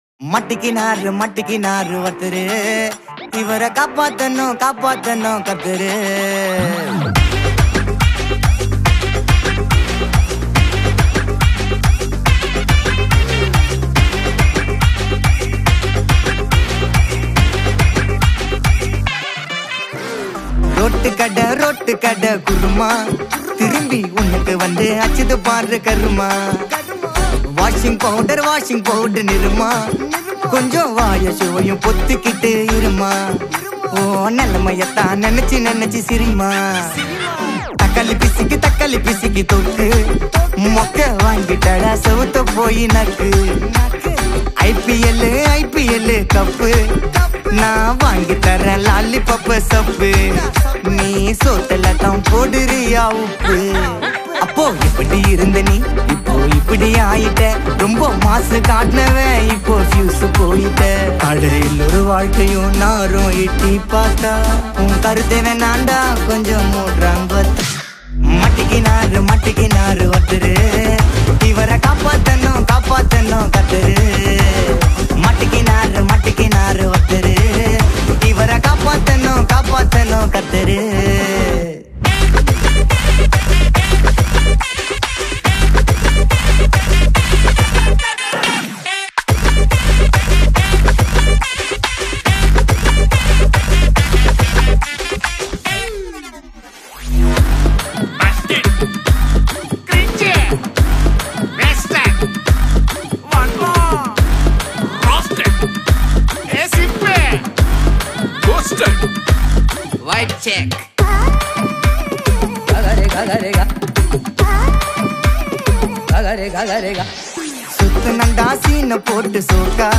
Releted Files Of Tamil Gana